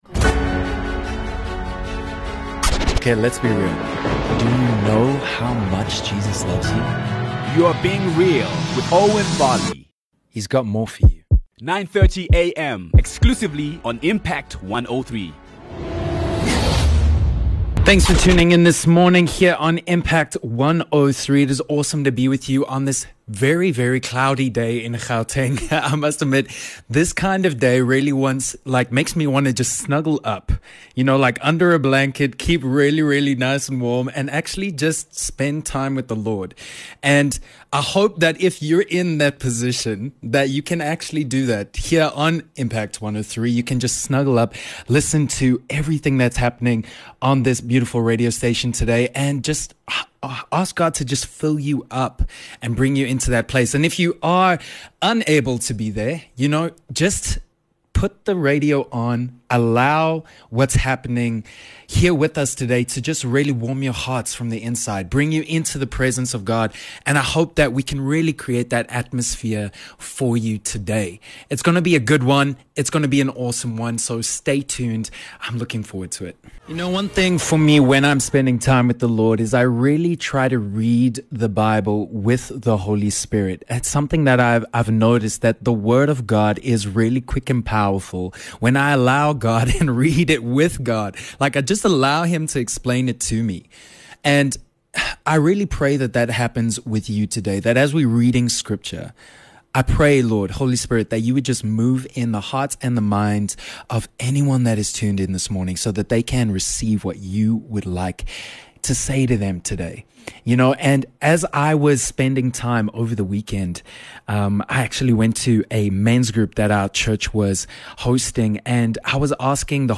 reads scripture on how God makes all things possible, and gives a powerful testimony on how God , through the Holy Spirit, used him, a normal man, to see a miracle.